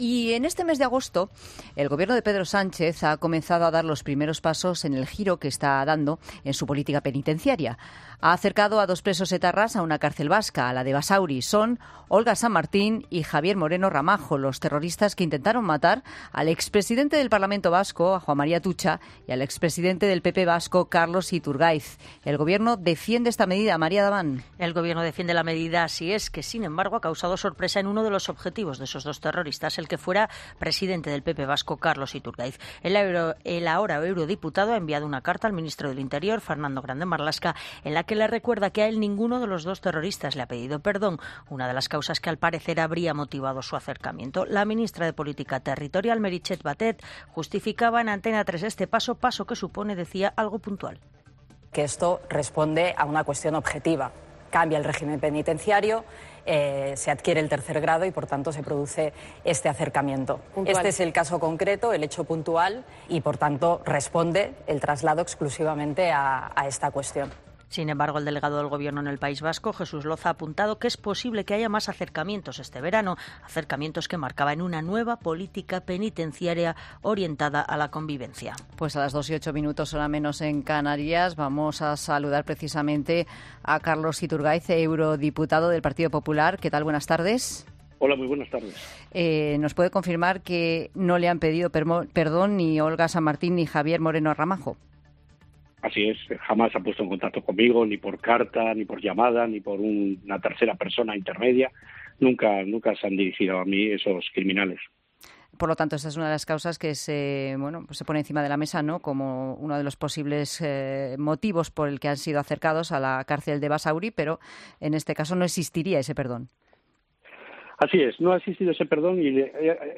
Iturgaiz ha participado en COPE para comentar esto.